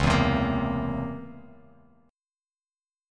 C#mM13 Chord
Listen to C#mM13 strummed